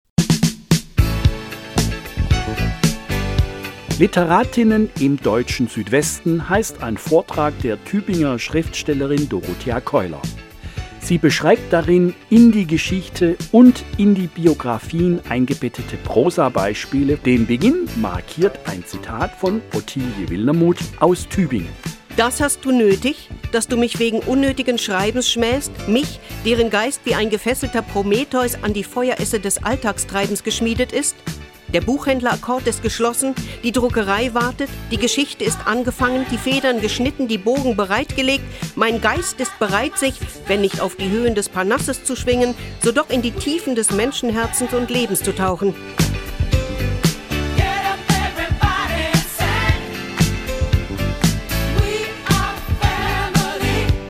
Es ist die Radiofassung eines Vortrags.
Teaser_543.mp3